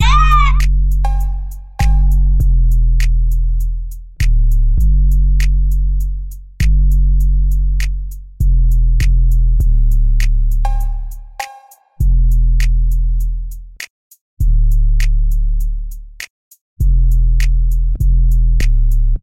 海岸线黑手党型鼓
描述：听起来像快节奏的流畅的鼓声，让人想起湾区的艺术家，如Shoreline Mafia或SOB X RBE。
标签： 100 bpm Trap Loops Drum Loops 3.23 MB wav Key : Unknown
声道立体声